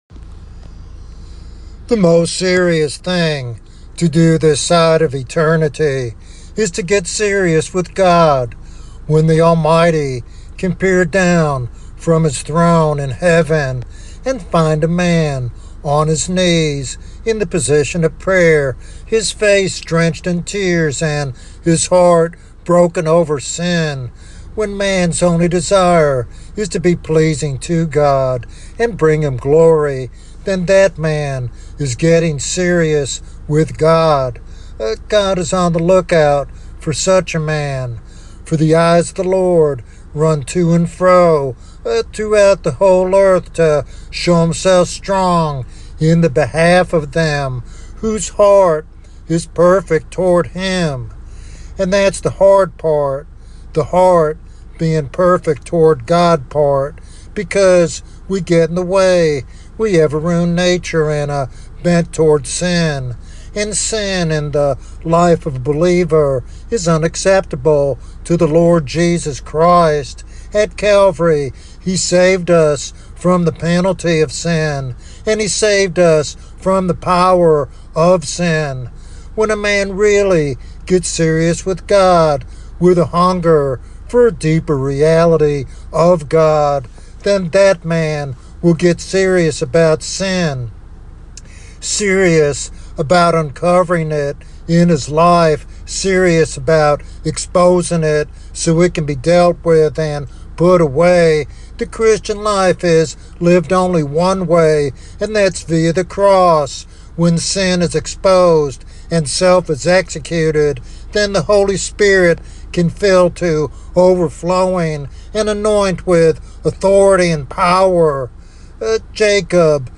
This devotional sermon encourages listeners to come clean with God, embrace repentance, and anticipate a victorious Christian walk empowered by the Holy Spirit.